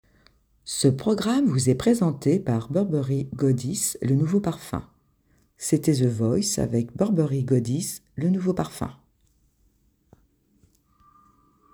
Voix off femme Billboard Burberry